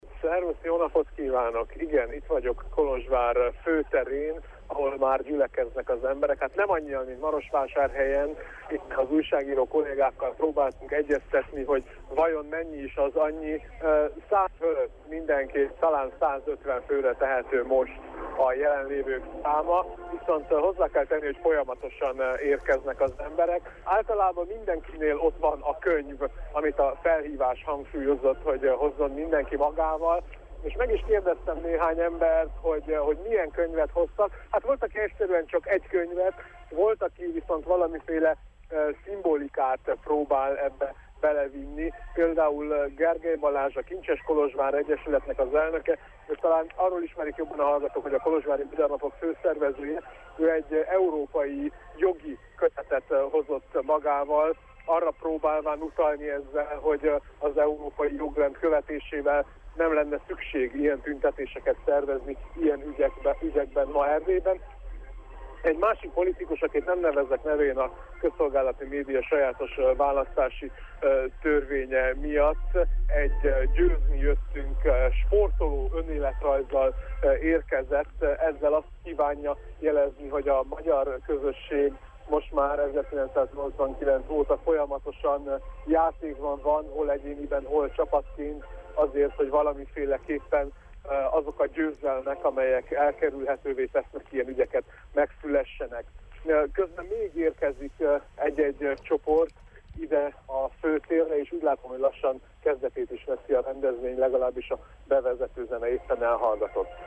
Marosvásárhelyen, a Vársétányon, Kolozsváron és Sepsiszentgyörgyön, a Főtéren, Nagyváradon az Ady múzeumnál, délután 3 órakor kezdődtek a szolidaritási akciók.